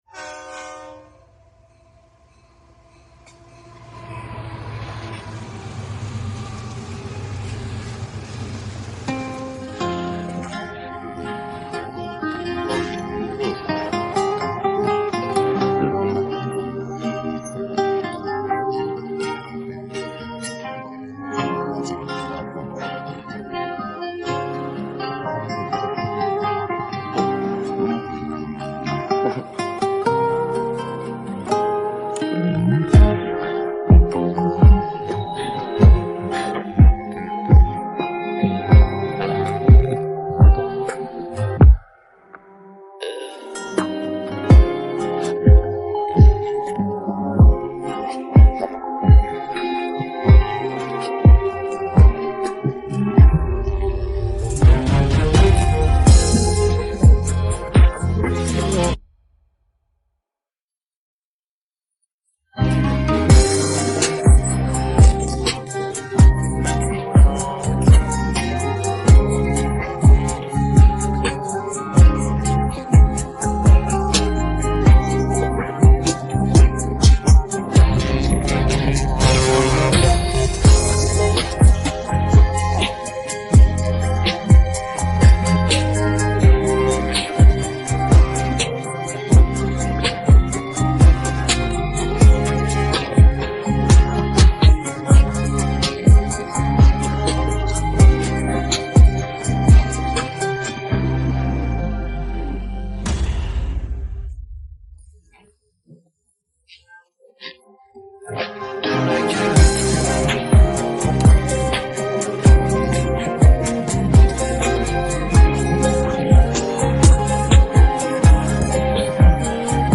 download-cloud دانلود نسخه بی کلام (KARAOKE)